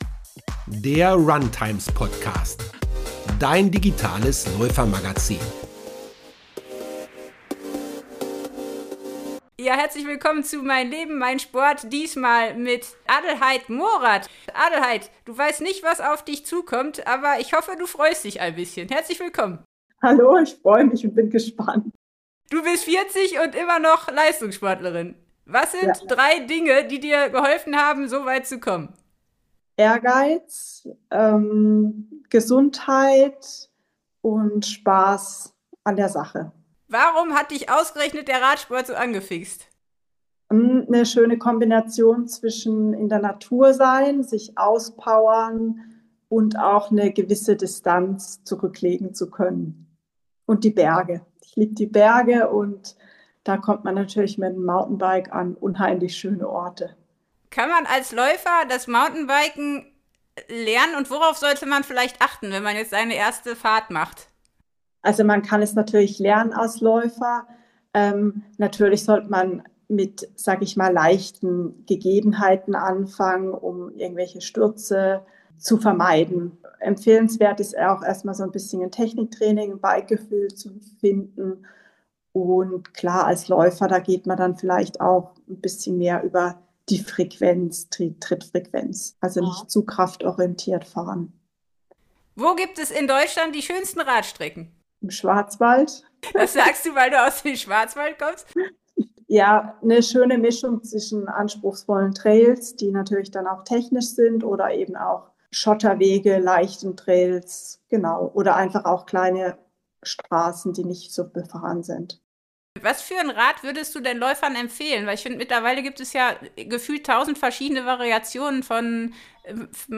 Unterhaltsame, lehrreiche und persönliche Gespräche mit Athleten aus der Trailrunning- und Straßenlauf-Szene, Gesundheits- und Ernährungsexperten oder Hobbysportlern.